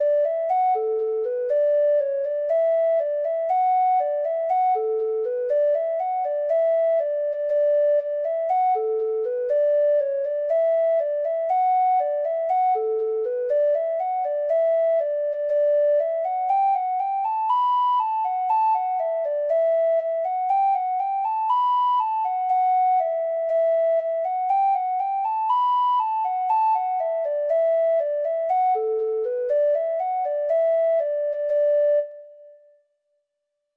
Free Treble Clef Instrument Sheet music
Irish